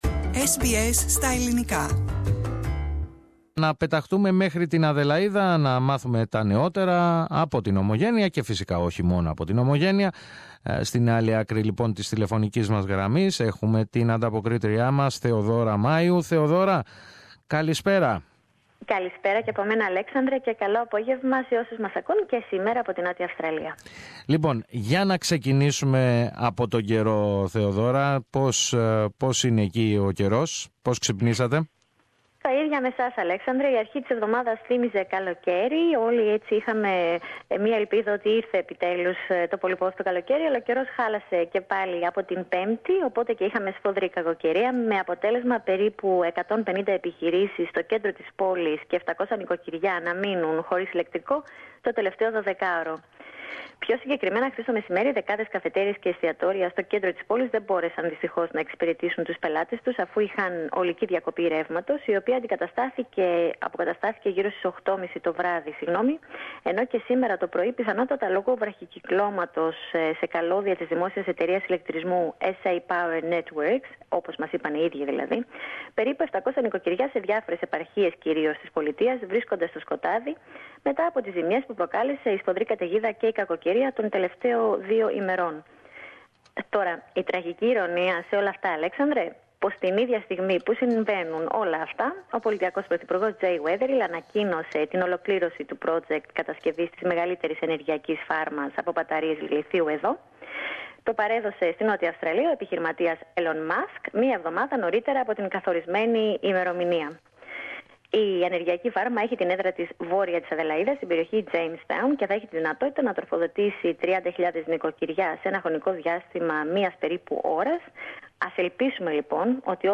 reports the latest news from SA.